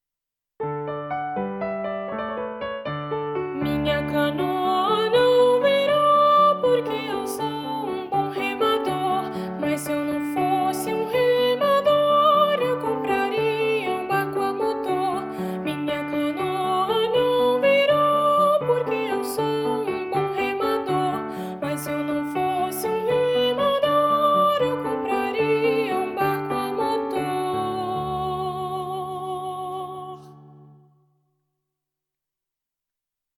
Voz Guia